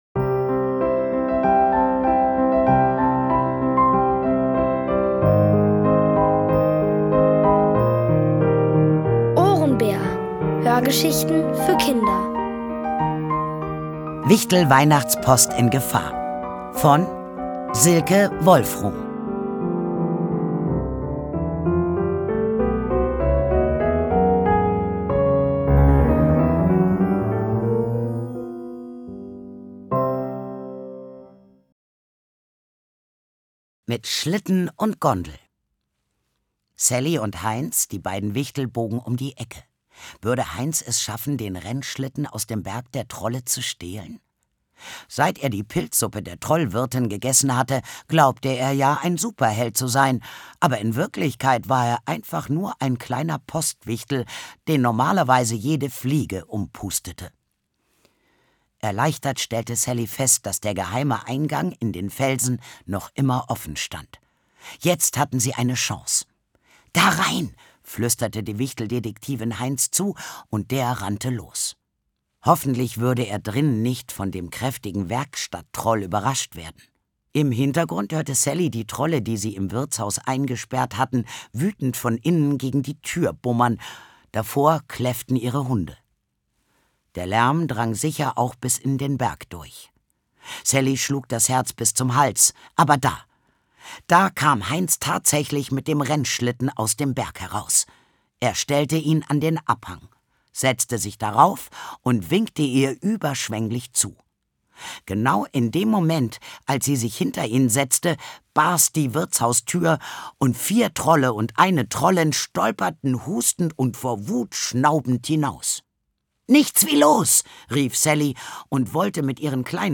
Aus der OHRENBÄR-Hörgeschichte: Wichtel-Weihnachts-Post in Gefahr! (Folge 6 von 7) von Silke Wolfrum. Es liest: Sandra Schwittau.